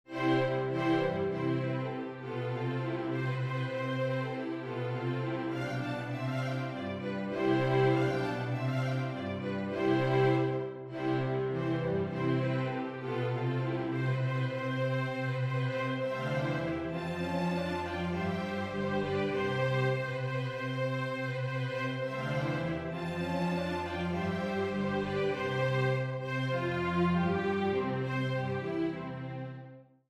Singspiel
Orchester-Sound